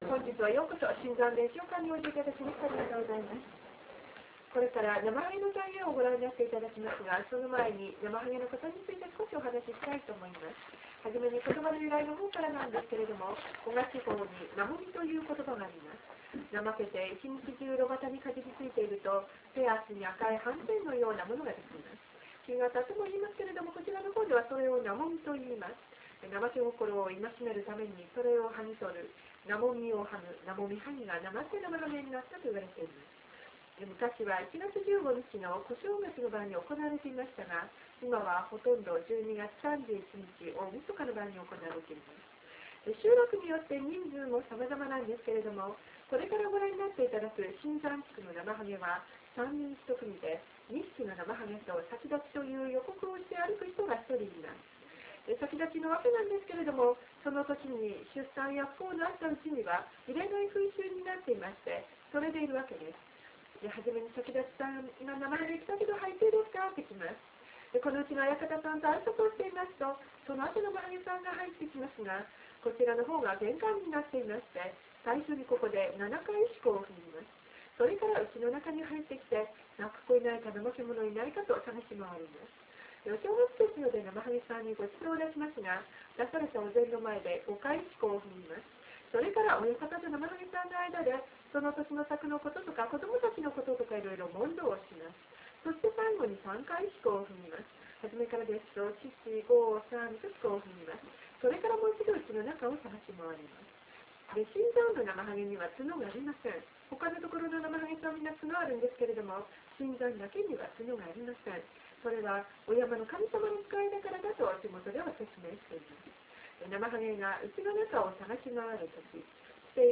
namahage3.wma